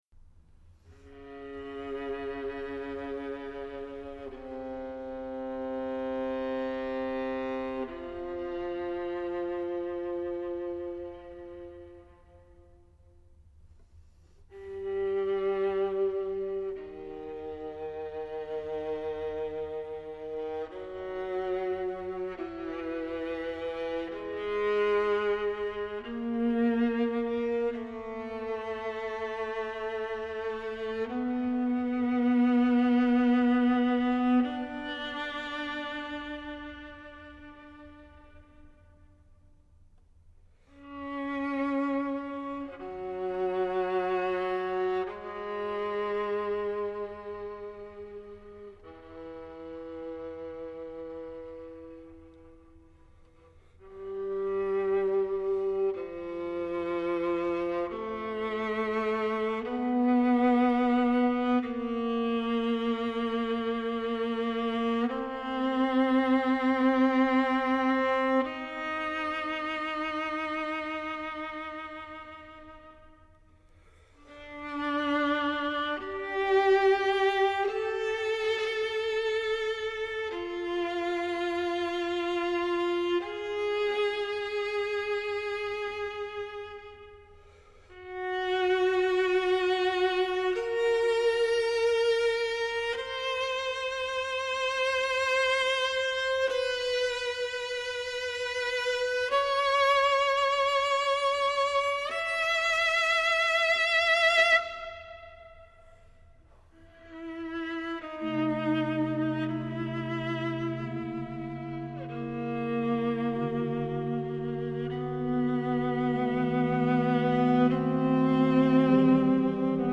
Orgel
Live im Lisztzentrum Raiding und der Pfarrkirche Horitschon